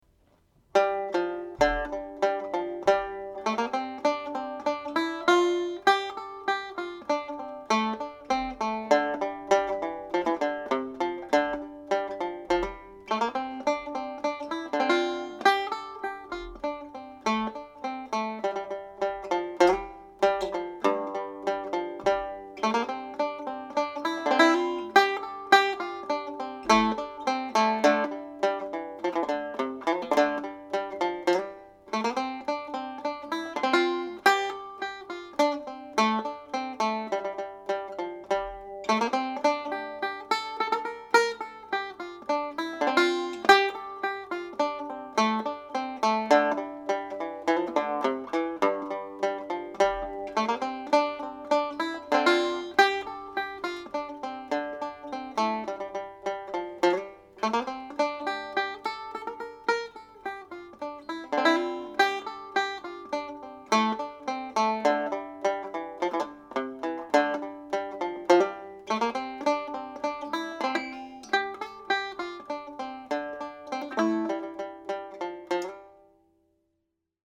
This is a hornpipe in the key of G model which is sometimes played as a reel.
Caisleán An Óir played slowly with some chords